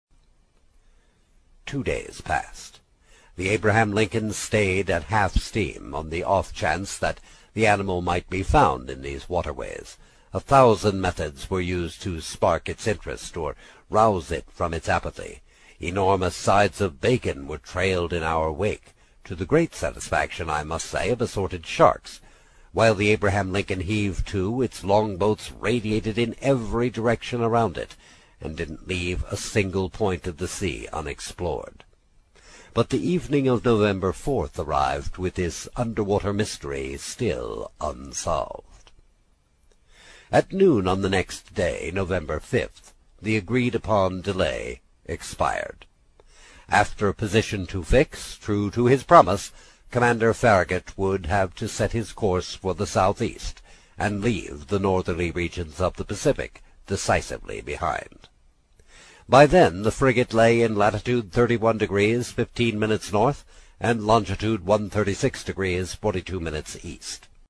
英语听书《海底两万里》第56期 第5章 冒险活动(11) 听力文件下载—在线英语听力室
在线英语听力室英语听书《海底两万里》第56期 第5章 冒险活动(11)的听力文件下载,《海底两万里》中英双语有声读物附MP3下载